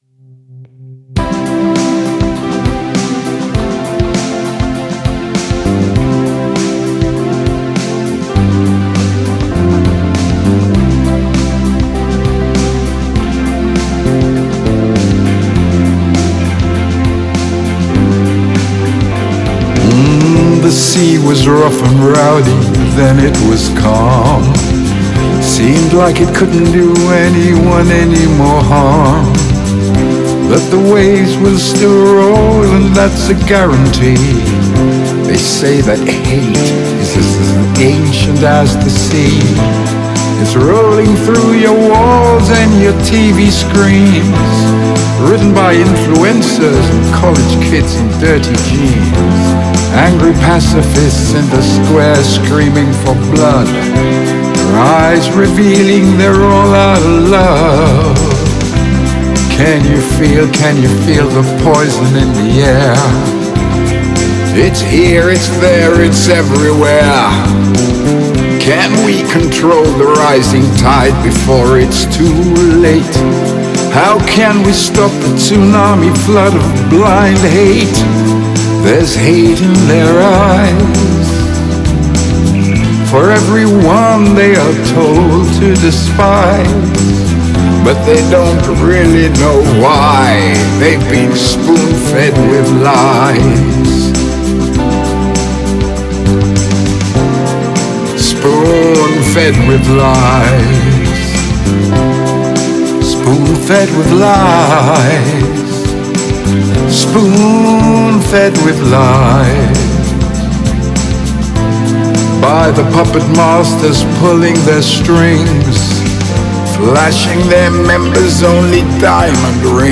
cautionary protest anthem